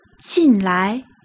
broken sound
jìnlai
The second is loving the neutral tone on the second syllable, which seems appropriate for this word--it's also the one in use for this word.